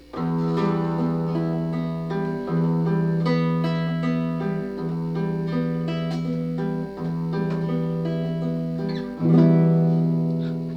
Afin de montrer l'existence d'une différence de timbre, nous avons effectué deux enregistrements
guitare 1